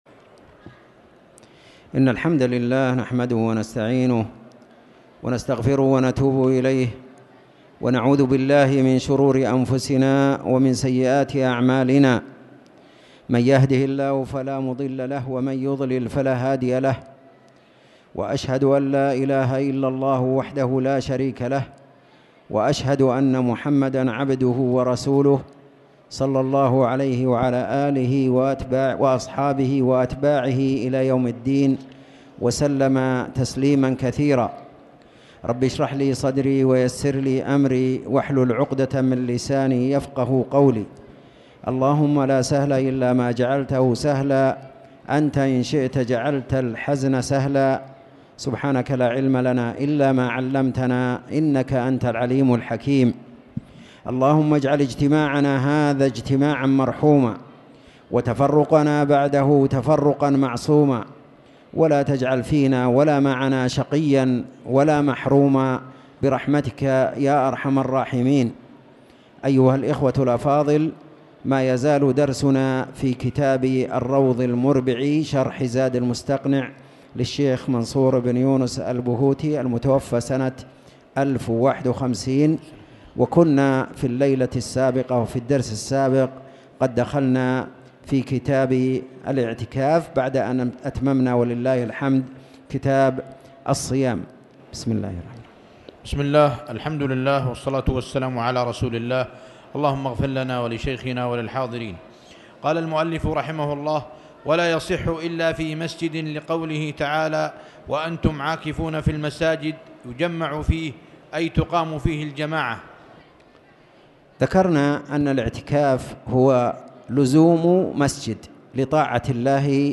تاريخ النشر ١٧ جمادى الأولى ١٤٣٨ هـ المكان: المسجد الحرام الشيخ